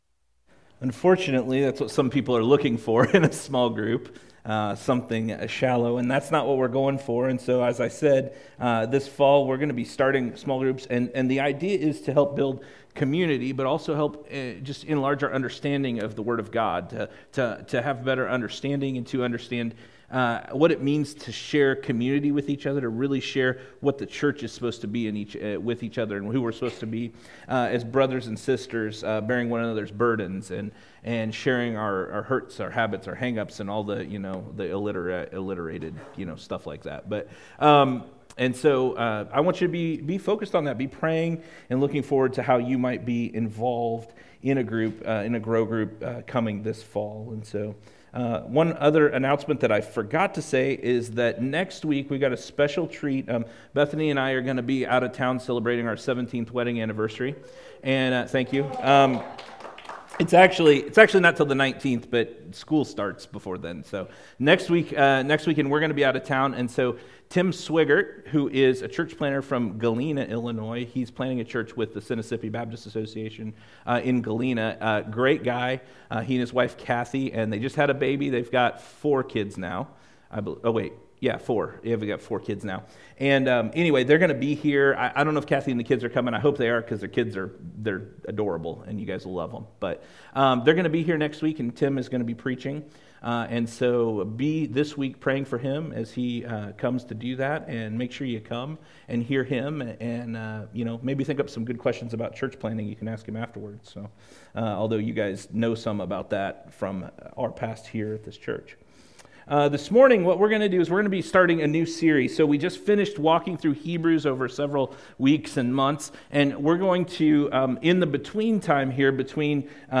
Weekly messages/sermons from GFC Ashton.